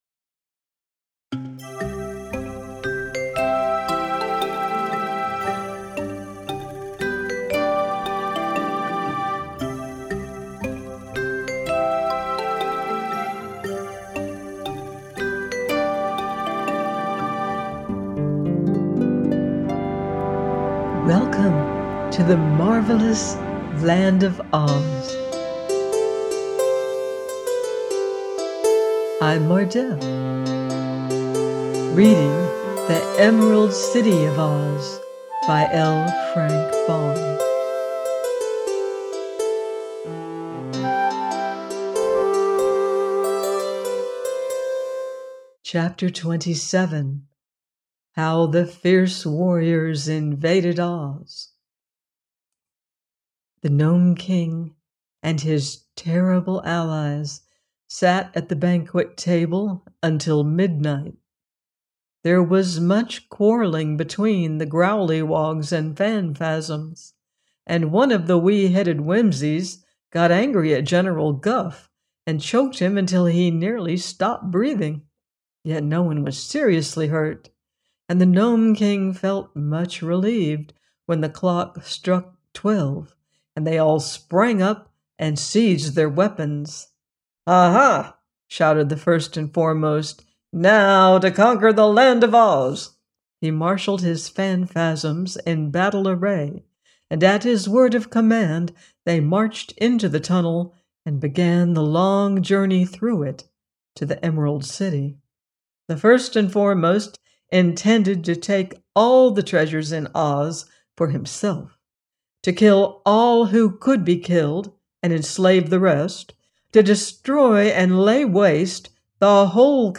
The Emerald City Of OZ – by L. Frank Baum - audiobook